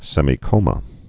(sĕmē-kōmə, sĕmī-)